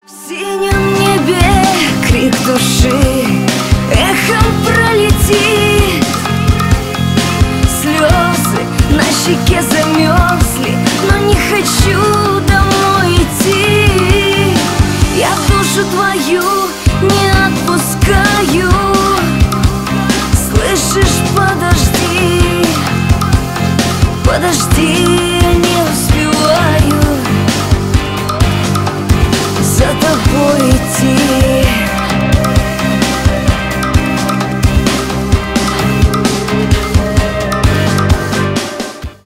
Шансон
грустные